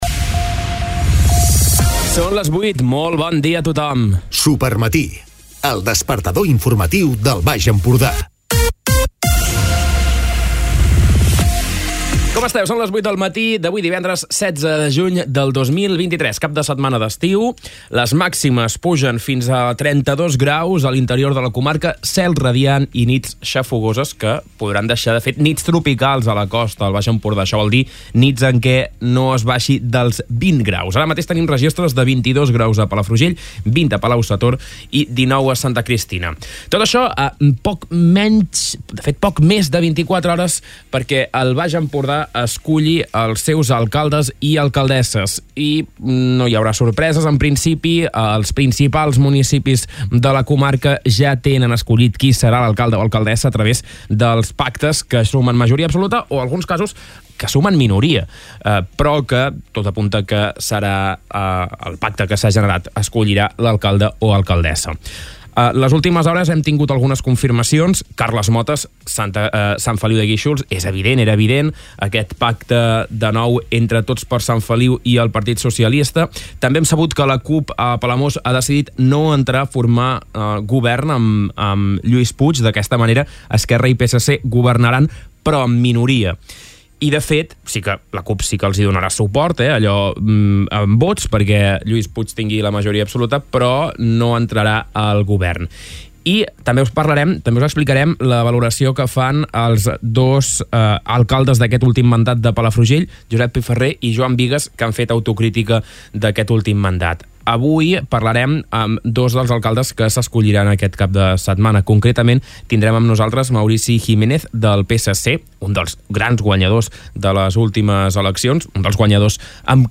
Escolta l'informatiu d'aquest divendres